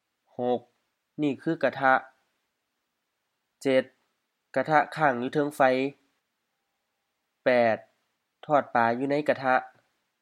IsaanPronunciationTonesThaiEnglish/Notes
กะทะ ga-tha M-H กะทะ frying pan
ค้าง kha:ŋ HF ค้าง to be positioned on {กะทะค้างอยู่เทิงไฟ = the frying pan is on the fire/oven}
Notes: pronunciation: also realized as ทัง